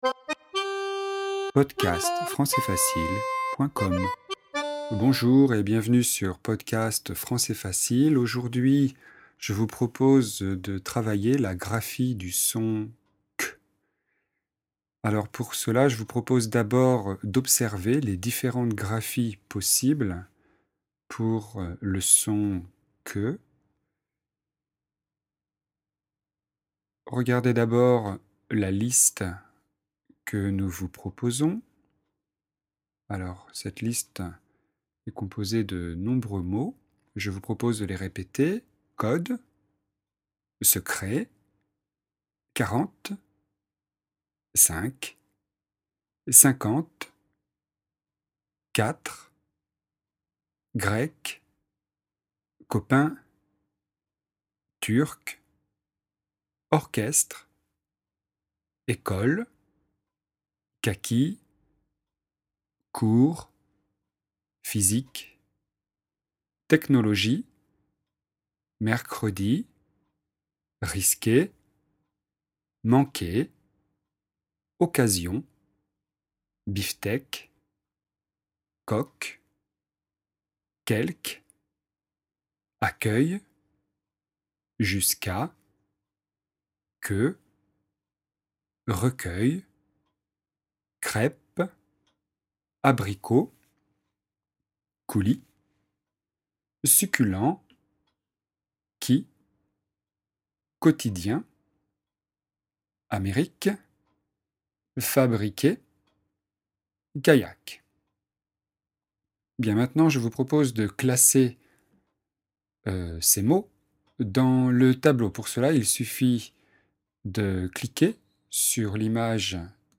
Leçon de phonétique et de prononciation.